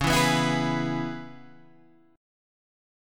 C# Minor 7th